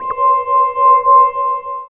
13 SCI FI -L.wav